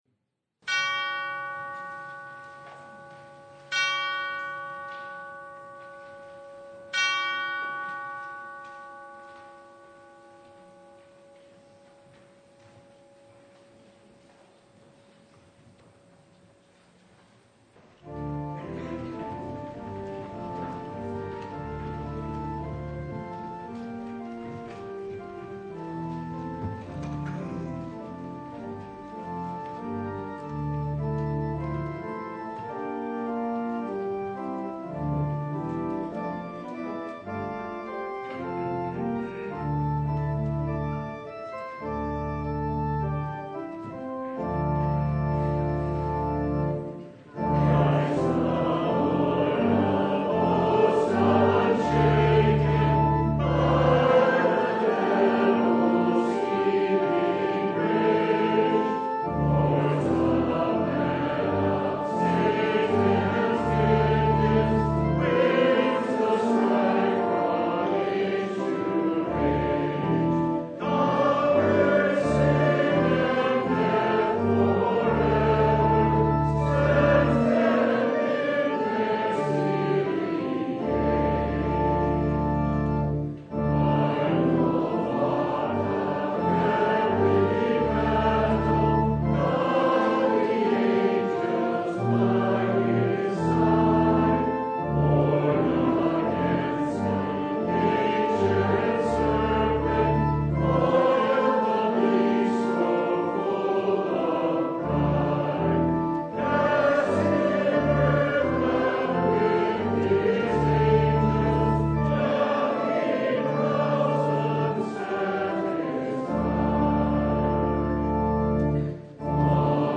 Hebrews 10:11-25 Service Type: Sunday As the Day draws near